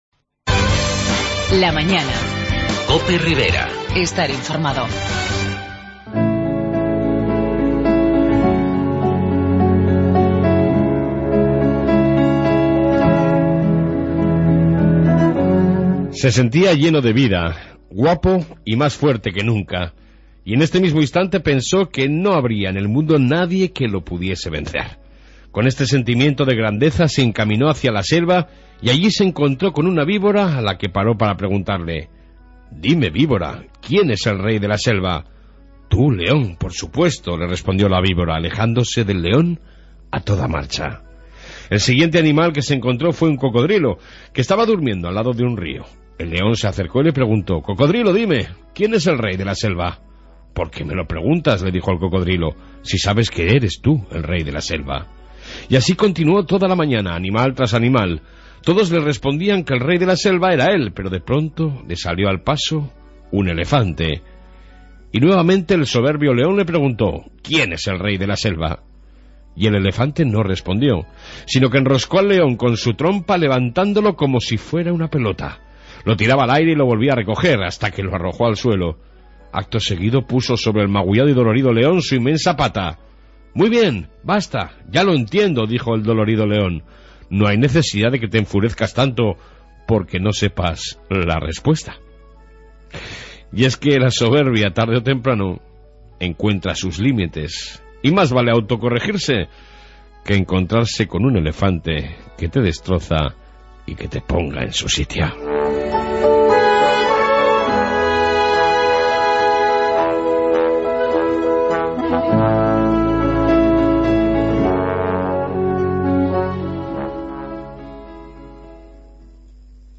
AUDIO: Reflexión Matutina, Informe Policía Municipal y entrevista sobre el nuevo Tudelano